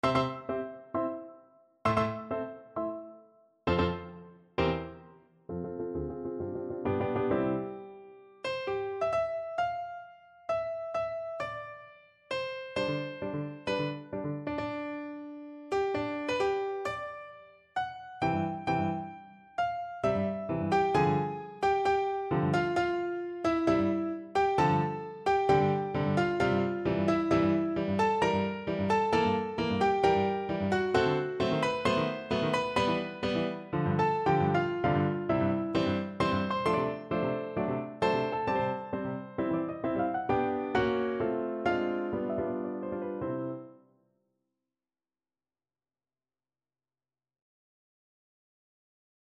Classical Berlioz, Hector Idee fixe from Symphonie Fantastique Piano version
No parts available for this pieces as it is for solo piano.
C major (Sounding Pitch) (View more C major Music for Piano )
Allegro agitato e appassionato assai = 132 (View more music marked Allegro)
2/2 (View more 2/2 Music)
Piano  (View more Advanced Piano Music)
Classical (View more Classical Piano Music)